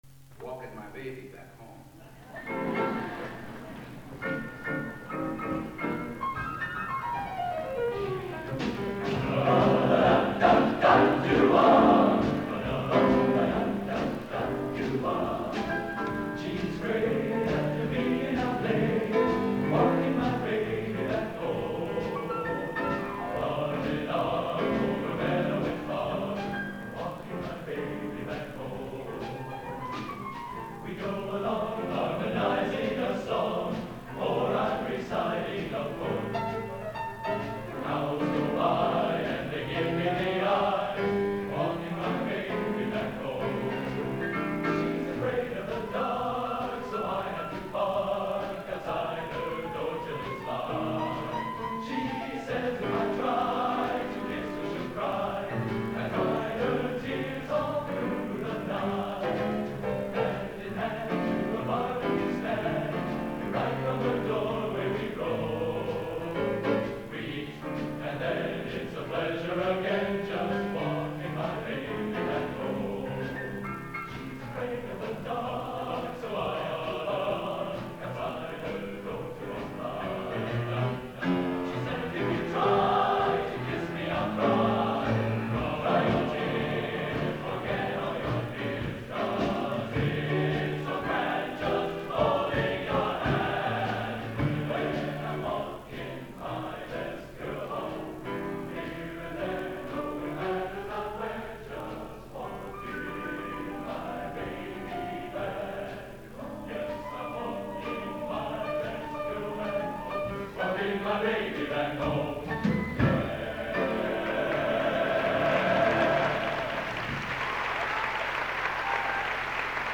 Genre: Schmalz | Type: